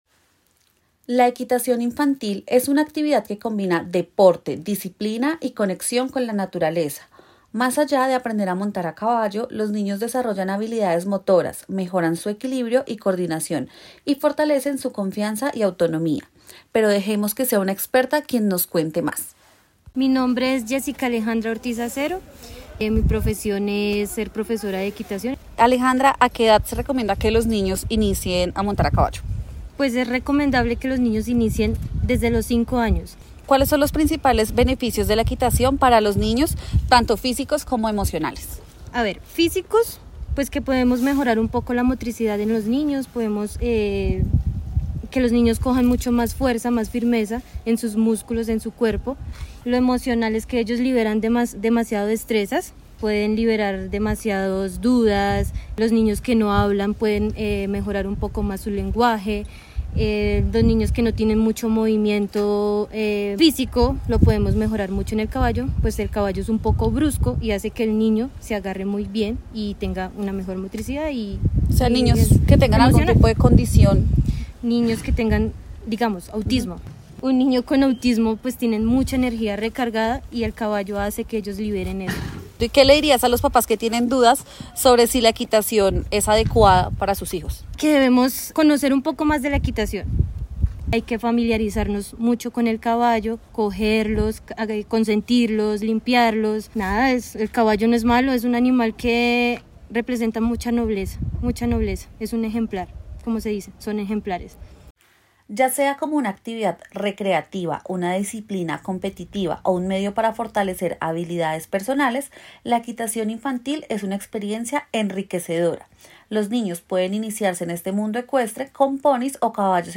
Esto dicen los especialistas en Equitación infantil